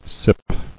[sip]